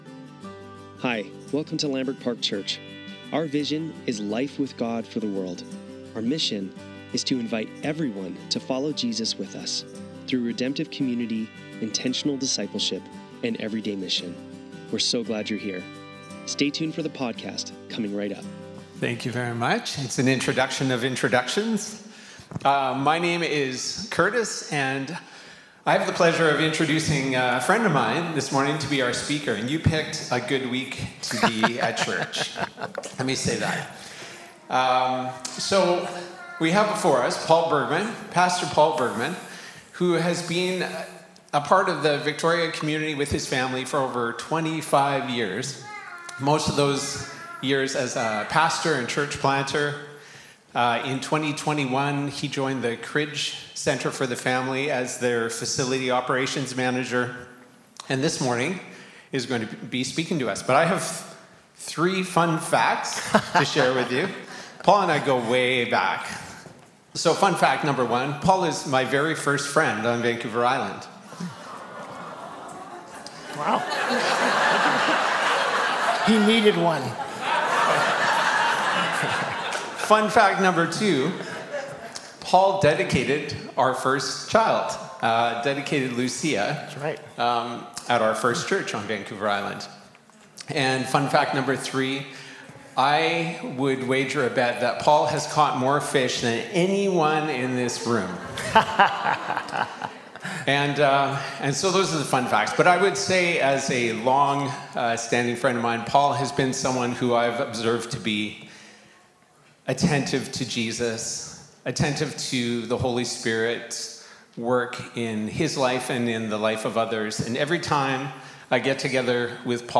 Sunday Service - September 7, 2025
Guest Speaker